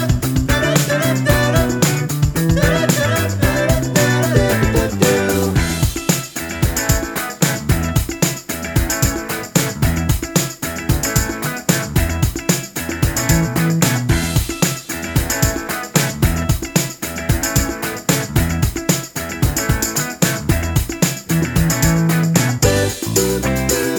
Minus Main Guitar Disco 3:12 Buy £1.50